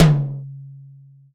• High Floor Tom Drum One Shot D Key 29.wav
Royality free tom drum one shot tuned to the D note. Loudest frequency: 730Hz
high-floor-tom-drum-one-shot-d-key-29-XEP.wav